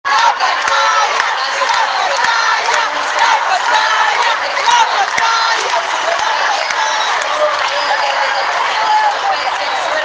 Desde este momento, hubo un comentario que se repitió entre los asistentes a la Quinta Vergara, principalmente en la zona de galería, puesto que durante todo el show no estuvieron encendidas las pantallas dispuestas al centro y a los lados del escenario.
Fue así como durante varios minutos el público gritaba a viva voz que encendieran las pantallas.
CUNAS-GRITOS.mp3